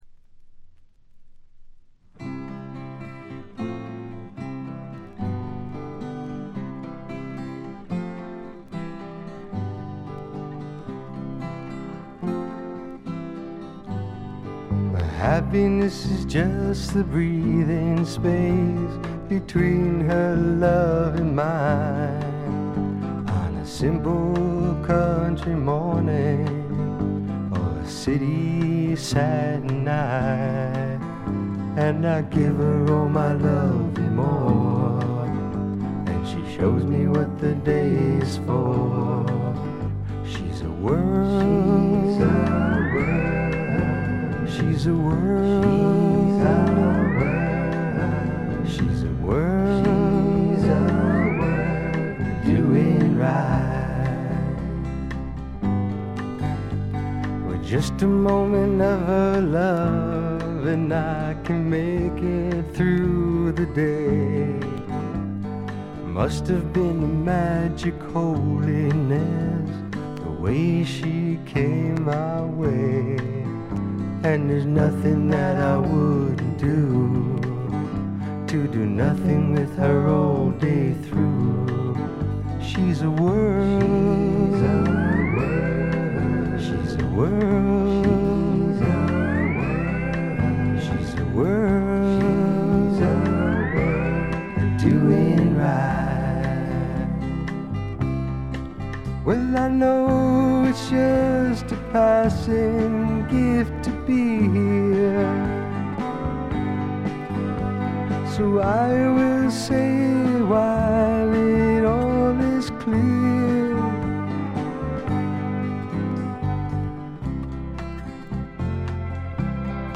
微細なチリプチがほんの少し。
試聴曲は現品からの取り込み音源です。
こちらもご覧ください　 レコード：米国 SSW / フォーク